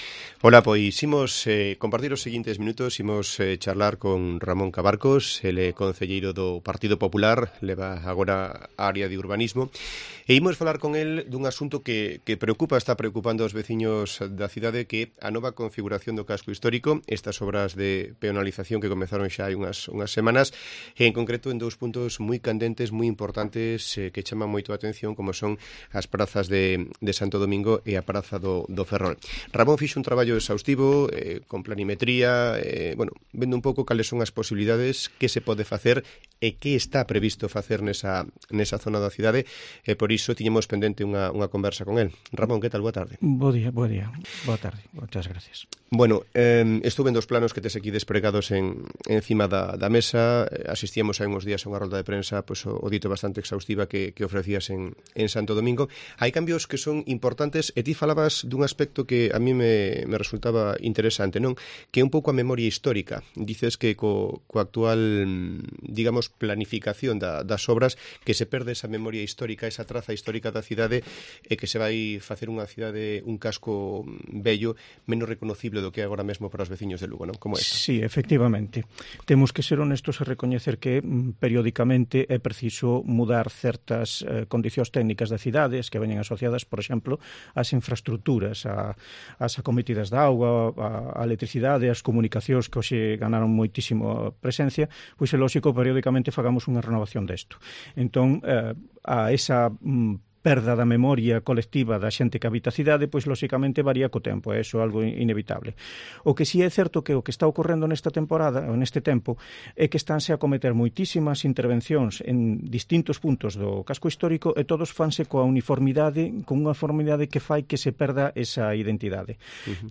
ENTREVISTA A RAMÓN CABARCOS